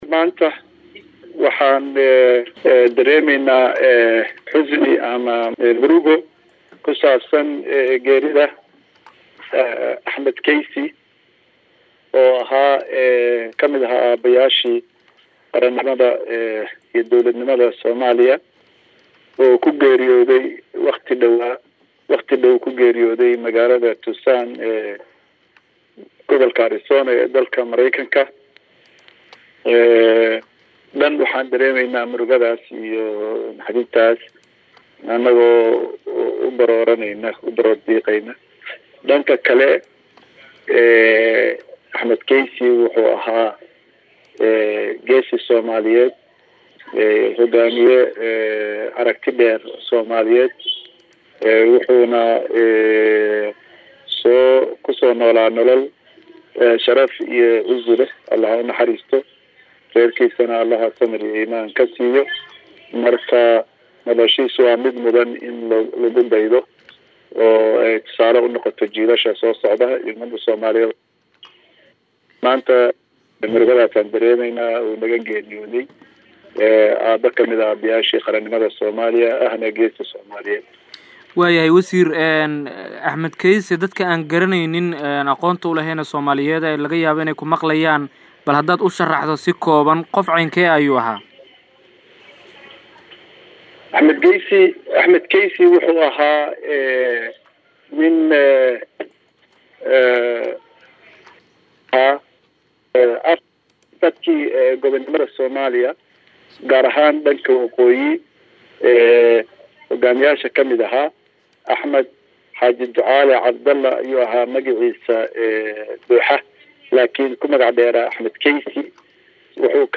Wasiirka arrimaha dibada Soomaaliya, Danjire Axmed Ciise Cawad oo wareysi siiyay Radio Muqdisho ayaa ka tacsiyeeyay, geerida Allaha u naxariistee
WAREYSIGA-WASIIRKA-ARRIMAHA-DIBADA-SOOMAALIYA-EE-GEERIDA.mp3